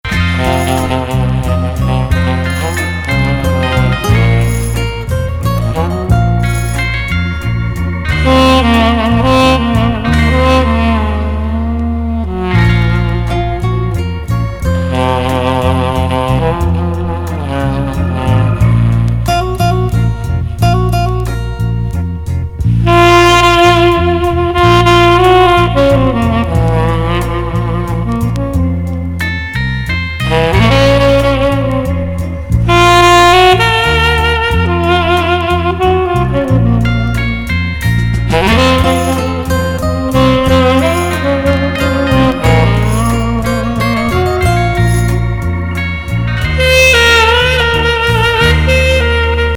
テナー・サックス・インスト・ファンク